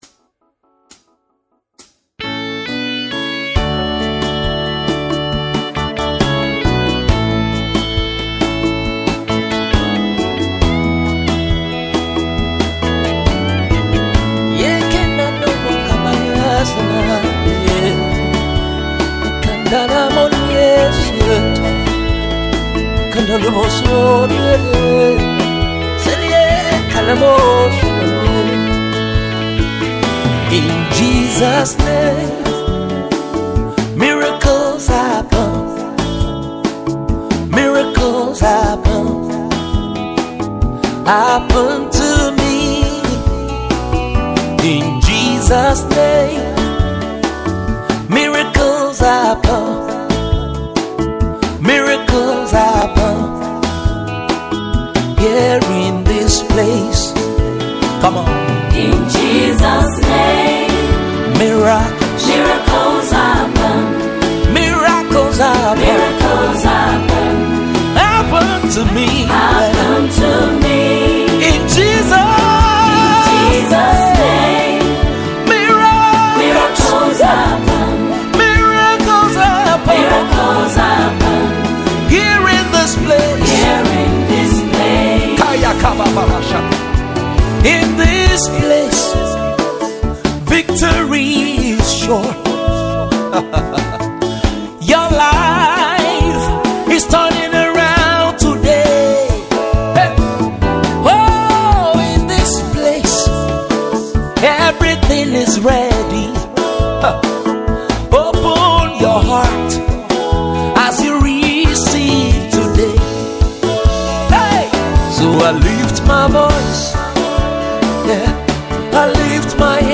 affirmation worship song